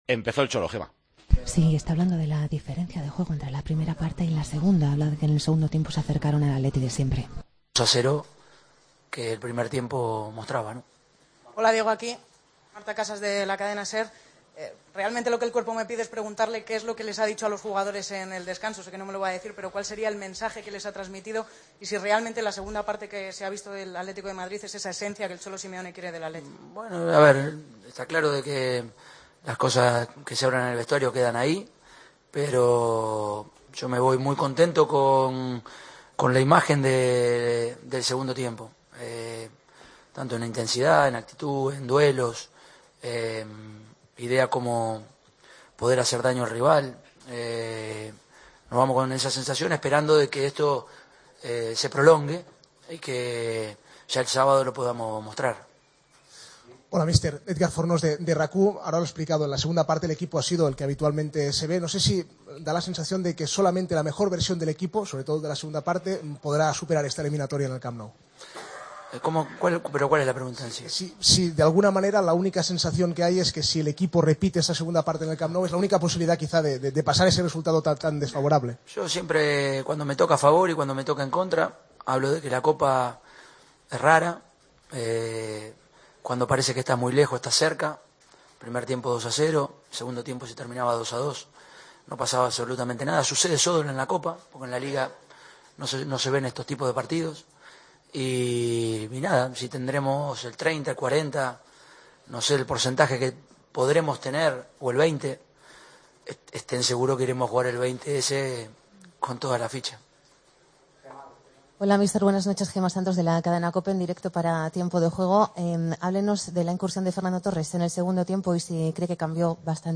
Escucha la rueda del entrenador del Atlético de Madrid al término de la ida de semifinales de la Copa del Rey: "Me voy contento con la imagen del segundo tiempo, con la idea de poder hacer daño al rival, y deseando de que esto se prolongue el sábado. Contribuyó tanto la entrada de Fernando como la de Gaitán y Gameiro, pero el cambio en general del equipo se notó claramente".